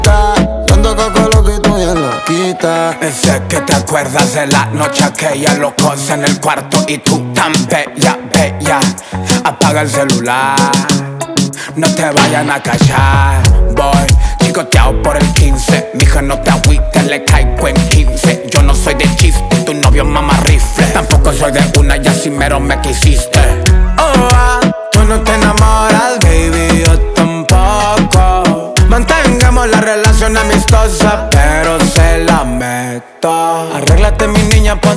Música Mexicana Latin